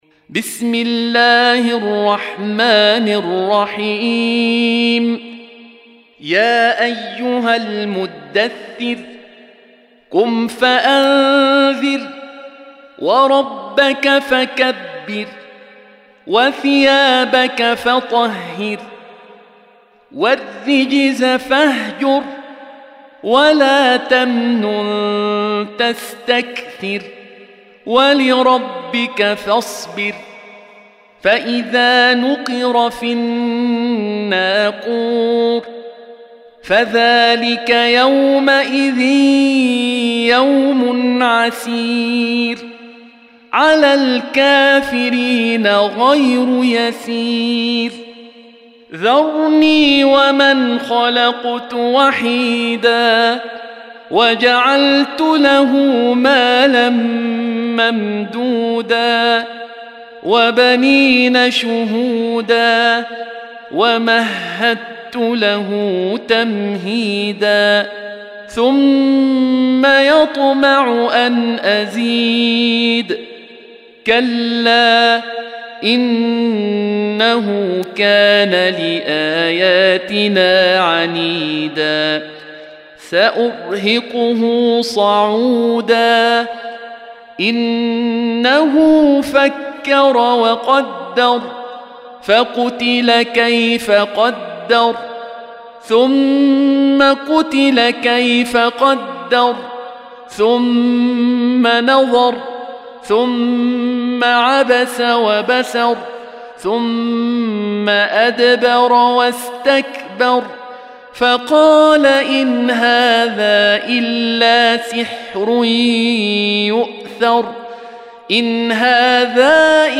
Surah Sequence تتابع السورة Download Surah حمّل السورة Reciting Murattalah Audio for 74. Surah Al-Muddaththir سورة المدّثر N.B *Surah Includes Al-Basmalah Reciters Sequents تتابع التلاوات Reciters Repeats تكرار التلاوات